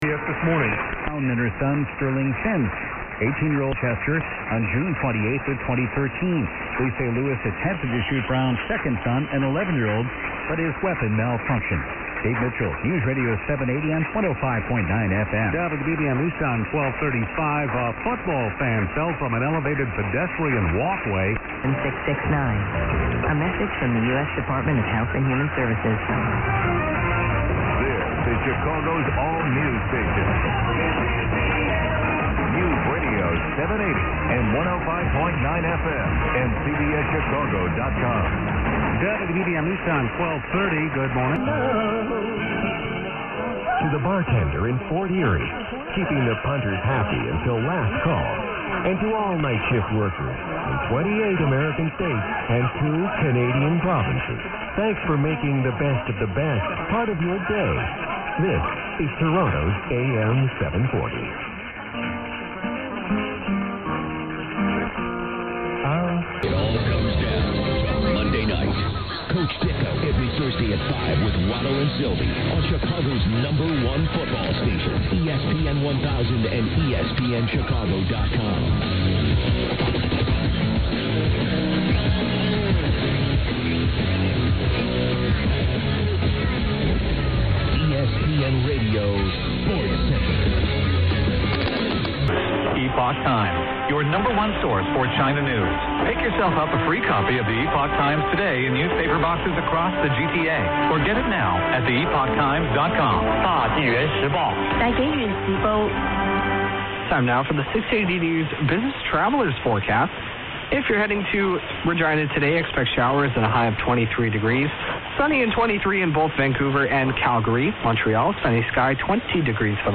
Montage of a few stations of 9th Sept
680 680 News Toronto, longish clip. booming.